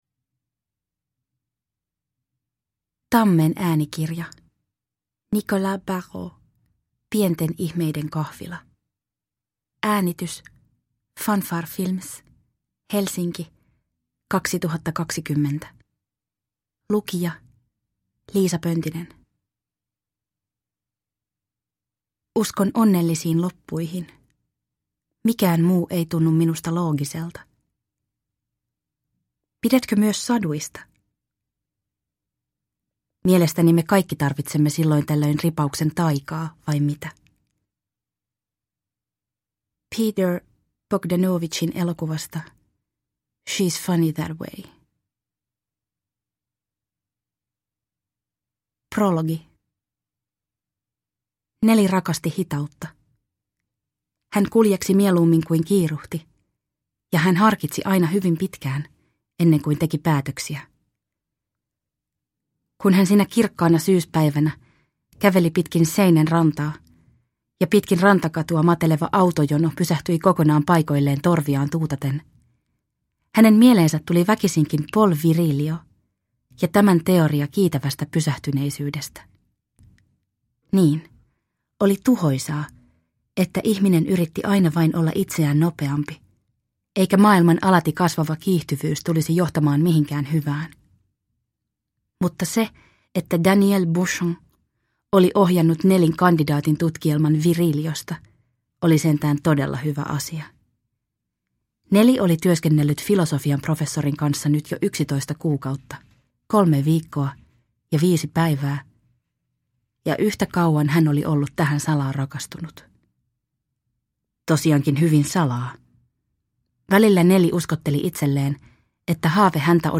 Pienten ihmeiden kahvila (ljudbok) av Nicolas Barreau